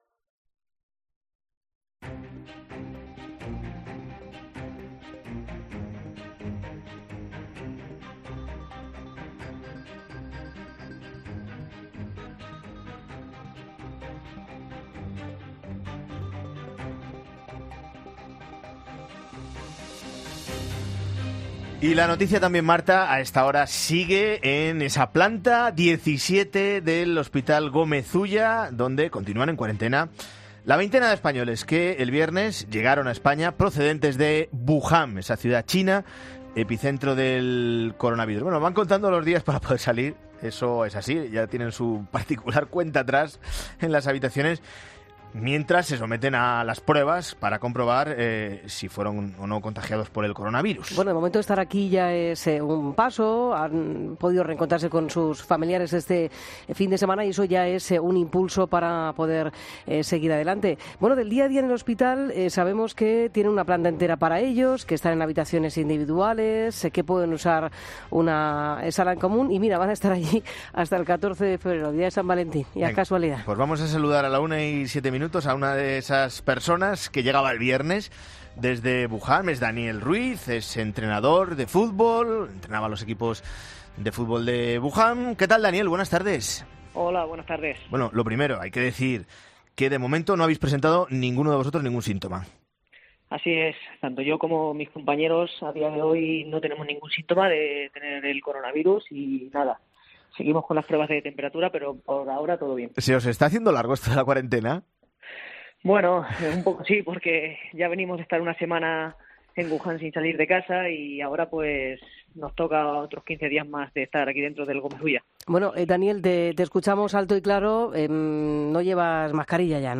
Los españoles en cuarentena en el Gómez Ulla dan un mensaje de tranquilidad